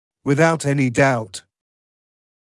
[wɪ’ðaut ‘enɪ daut][уи’заут ‘эни даут]без каких-то сомнений, несомненно